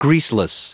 Prononciation du mot greaseless en anglais (fichier audio)
Prononciation du mot : greaseless